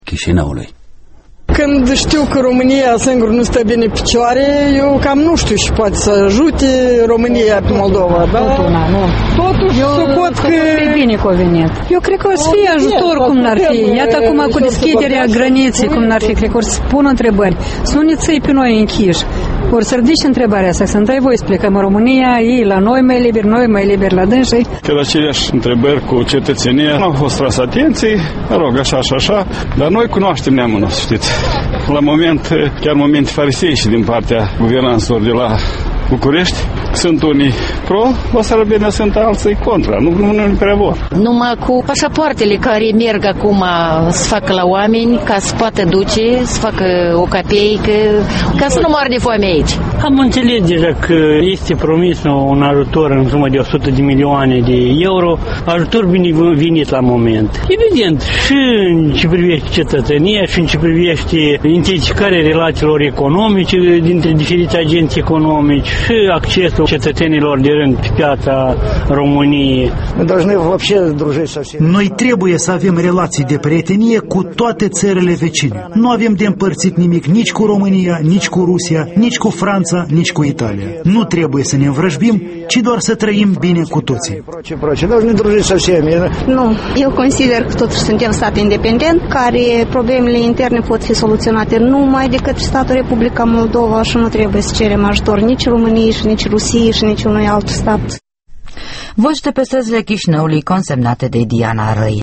Vox populi - aşteptările oamenilor de rînd de la vizita lui Traian Băsescu